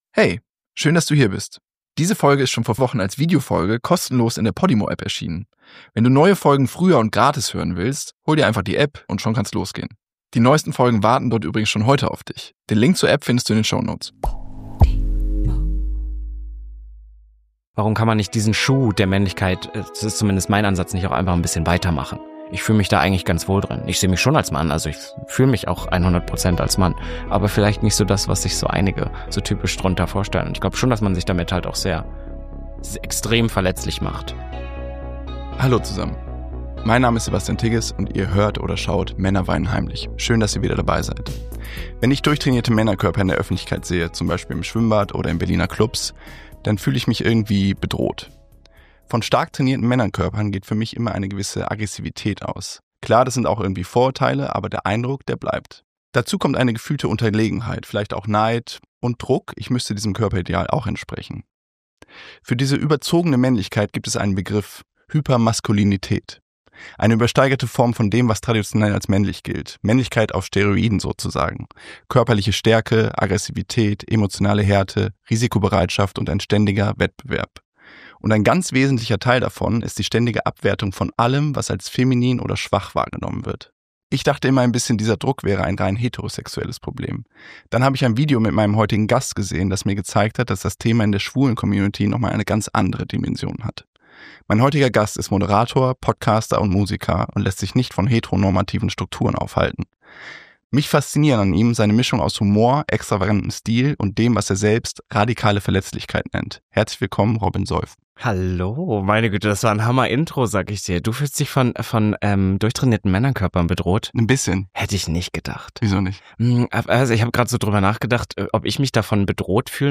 Ein Gespräch über die Brüche im eigenen Selbstbild, queerfeindliche Anfeindungen im Alltag, die Ambivalenz von Schönheitsidealen und den Mut, sich selbst treu zu bleiben.